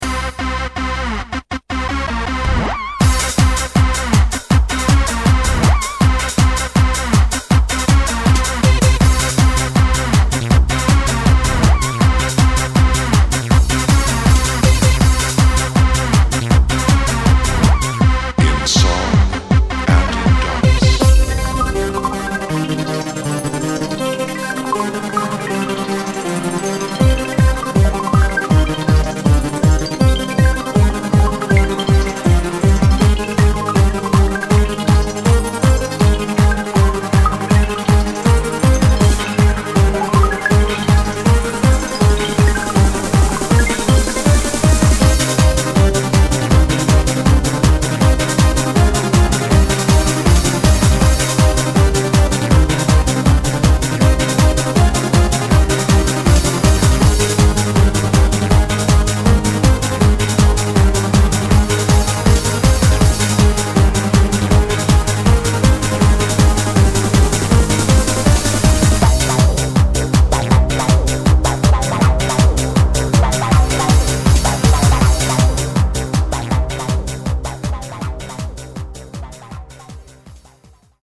Hard-Trance, Hardstyle, Trance
This is a special remastered edition!